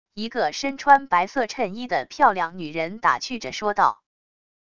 一个身穿白色衬衣的漂亮女人打趣着说道wav音频